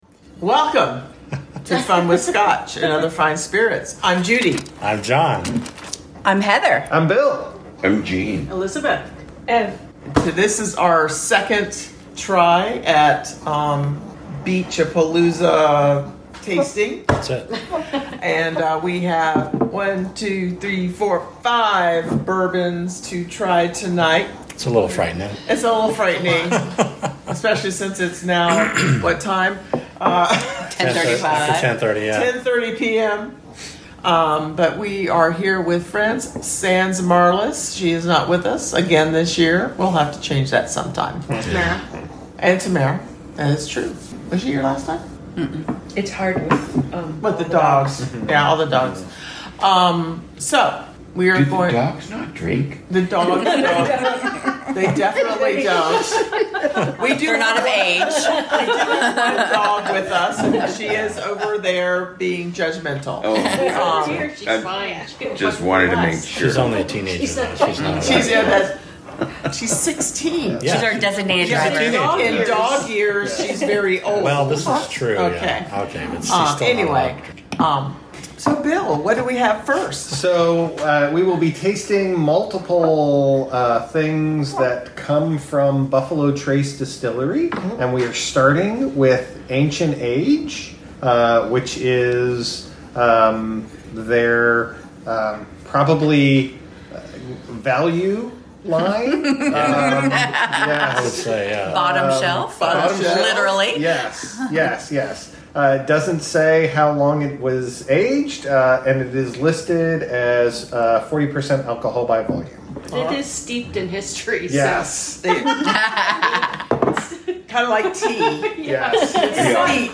We had a wonderful time making this podcast and we laughed a lot and learned a lot.